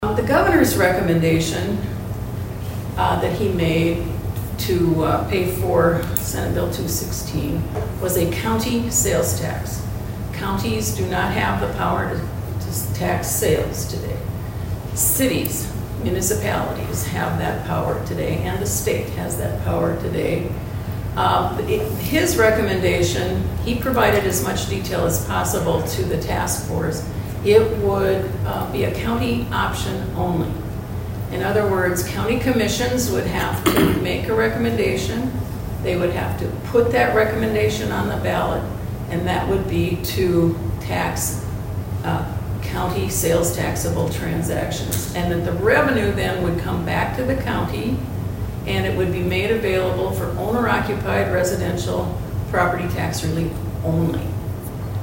ABERDEEN, S.D.(HubCityRadio)- The Aberdeen Chamber of Commerce’s Chamber Connections Series continue Thursday at the K.O.Lee Public Library.